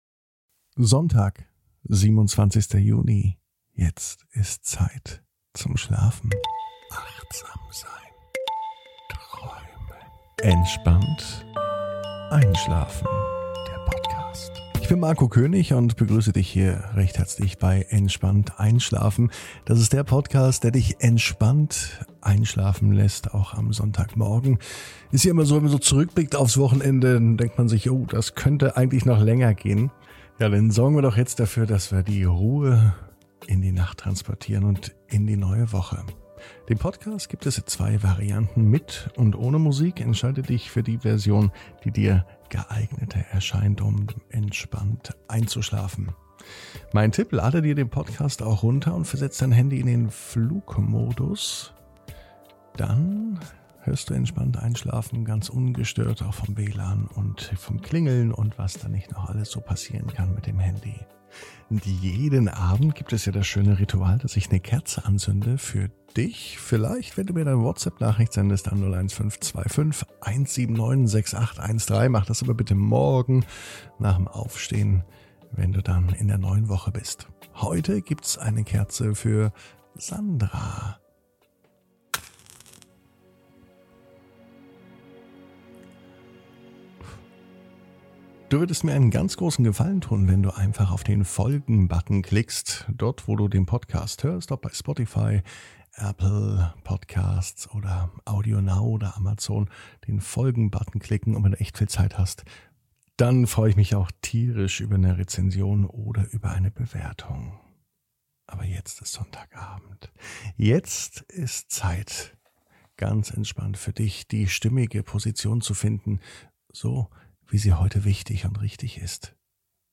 (ohne Musik) Entspannt einschlafen am Sonntag, 27.06.21 ~ Entspannt einschlafen - Meditation & Achtsamkeit für die Nacht Podcast